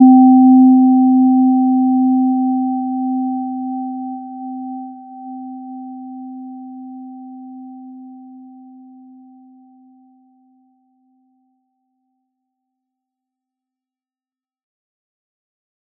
Gentle-Metallic-1-C4-p.wav